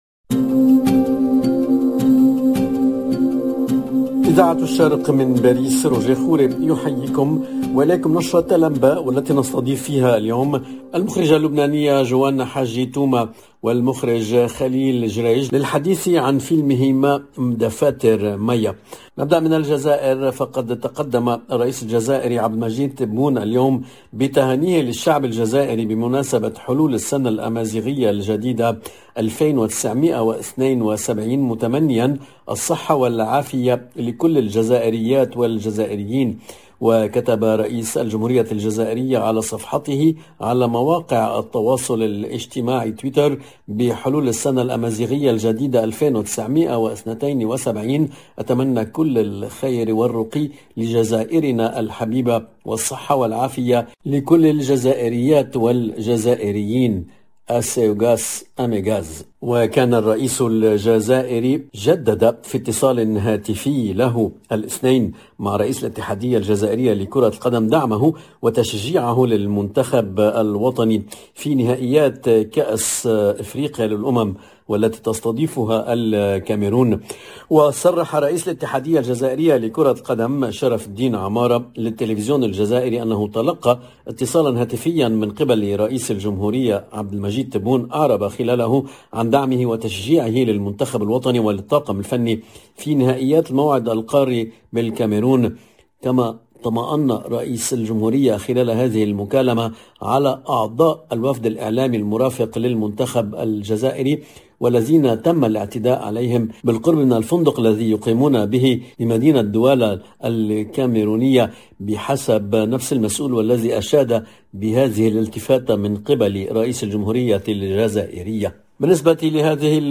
LE JOURNAL DU SOIR EN LANGUE ARABE DU 11/01/22